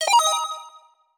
Archievement WAV.wav